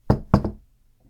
关于硬地面音效素材的高质量PPT_风云办公